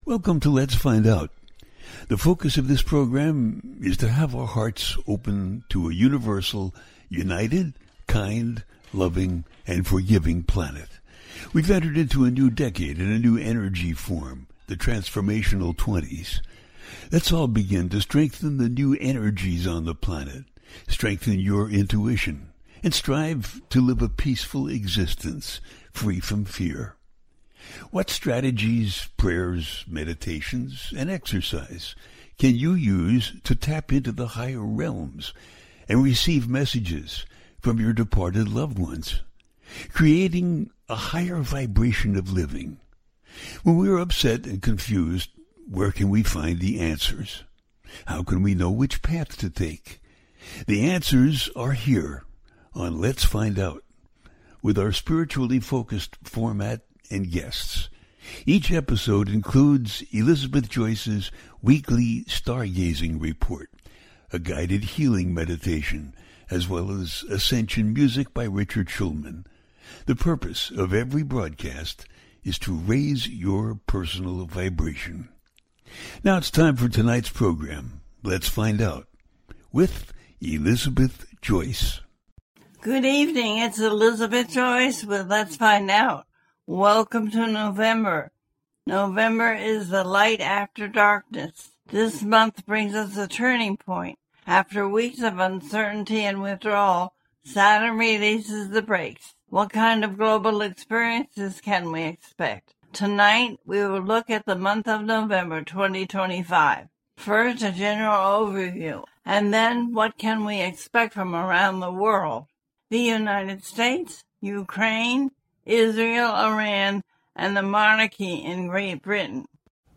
November is Light after the darkness, The Taurus full moon 2025 - A teaching show